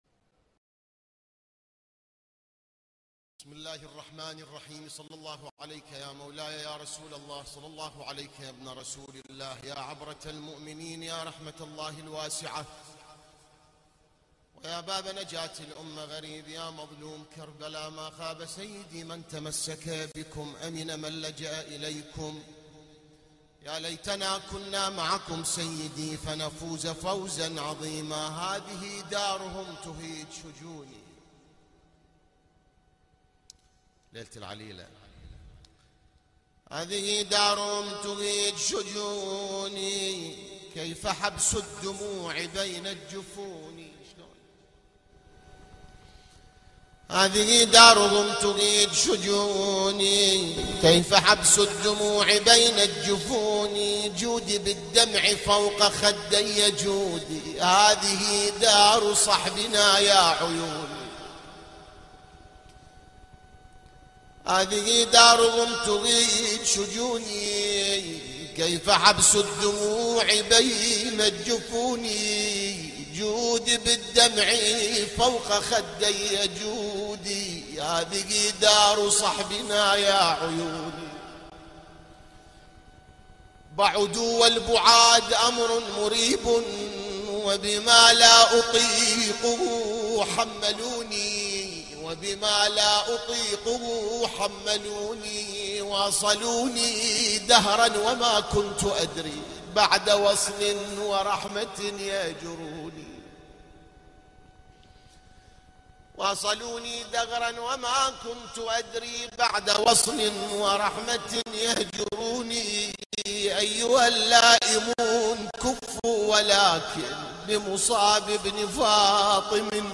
الان-مباشرة-ليلة-٣-محرم-١٤٤٦هـ-هيئة-الزهراء-للعزاء-المركزي-في-النجف-الاشرف.mp3